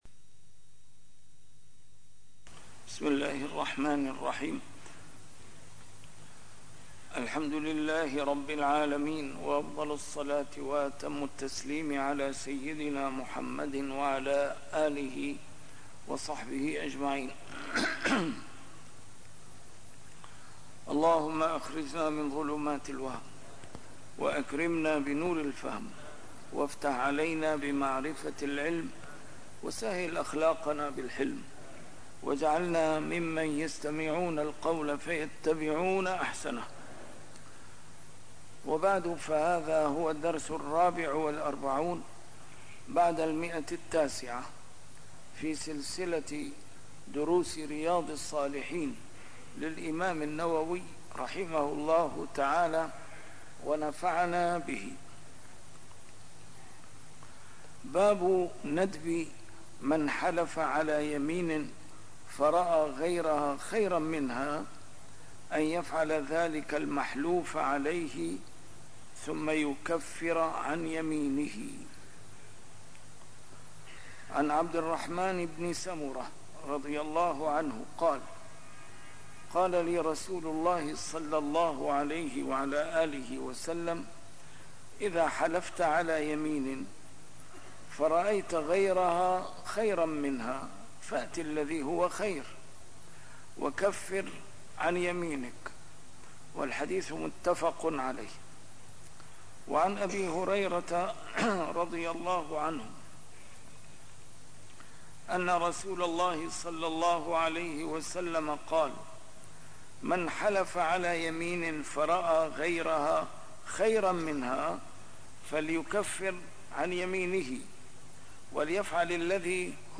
A MARTYR SCHOLAR: IMAM MUHAMMAD SAEED RAMADAN AL-BOUTI - الدروس العلمية - شرح كتاب رياض الصالحين - 944- شرح رياض الصالحين: من حلف على يمين فرأى غيرها خيراً منها - العفو عن لغو اليمين